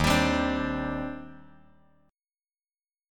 D#M13 Chord